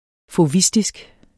Udtale [ foˈvisdisg ]